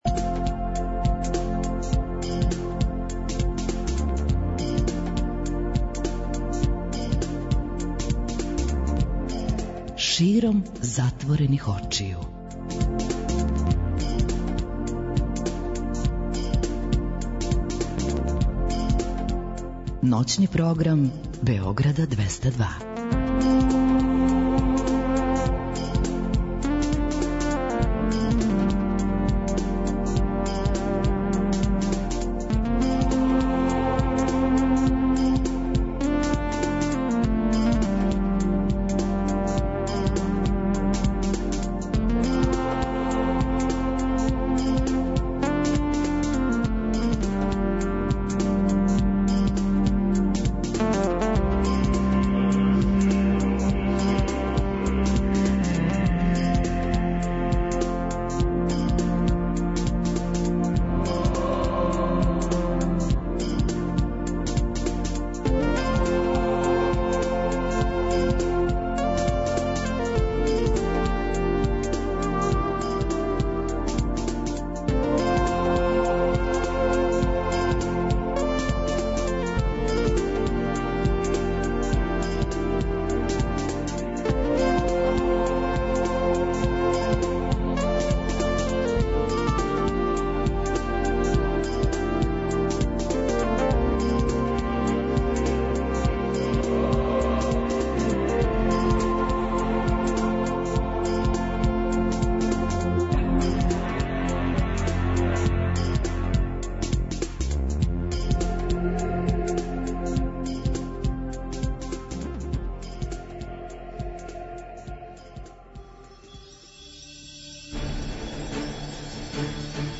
Ноћни програм Београда 202
Тако некако изгледа и колажни контакт програм "САМО СРЦЕМ СЕ ДОБРО ВИДИ".